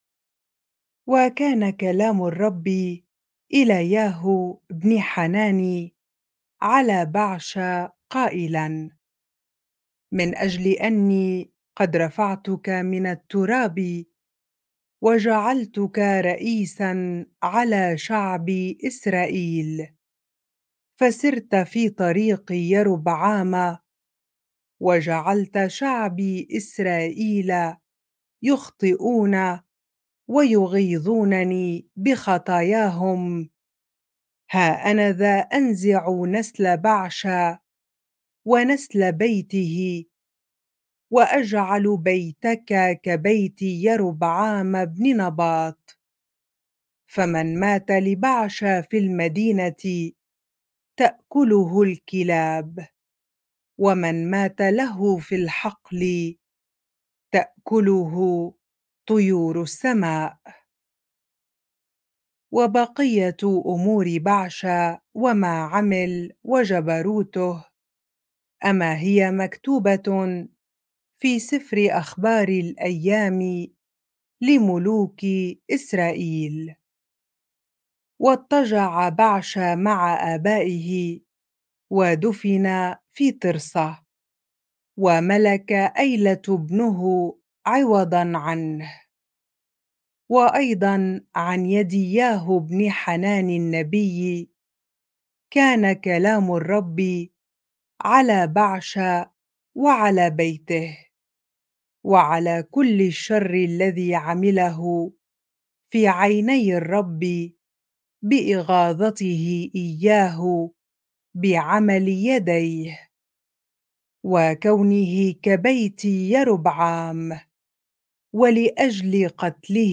bible-reading-1 Kings 16 ar